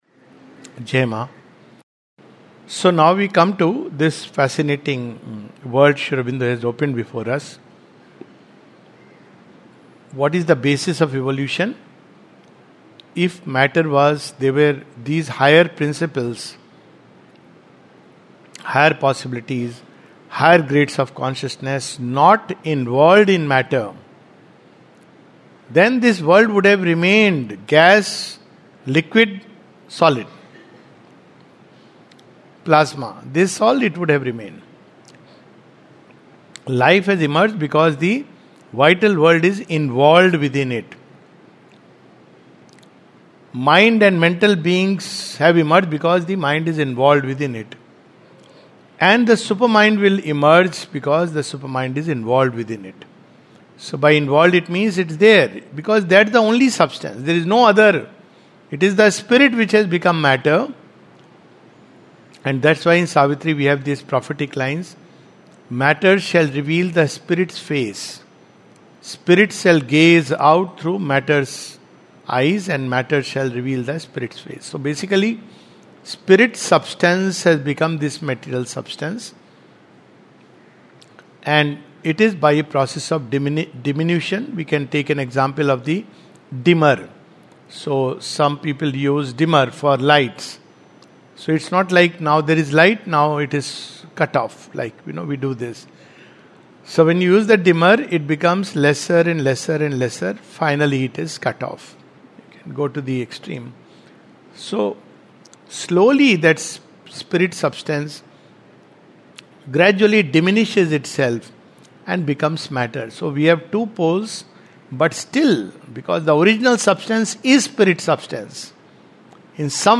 This interactive session covers almost every aspect of Death including the what is Death, why it exists, how Death takes place, the journey after Death, rebirth, rituals for the departed, pain of Death, after Death experience, religions and beliefs, moksha, heaven and hell etc.